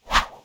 Close Combat Swing Sound 37.wav